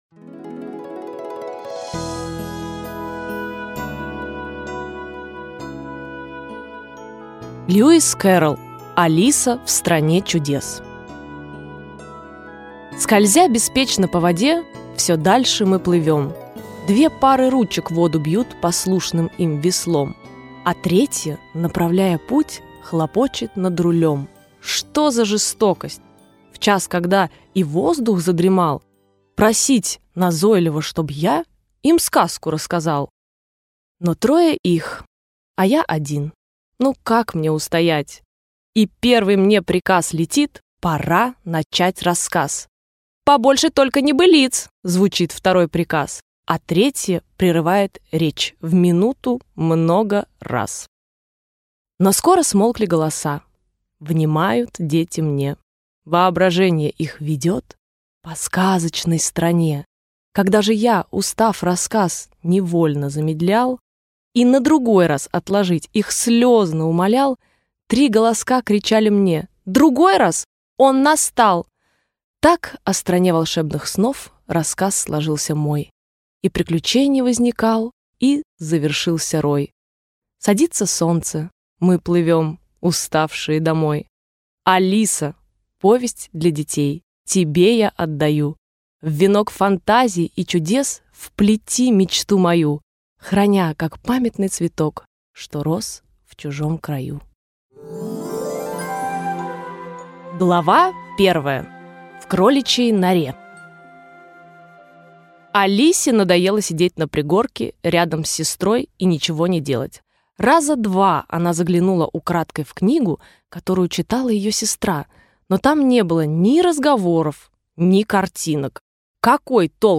Аудиокнига Алиса в стране чудес | Библиотека аудиокниг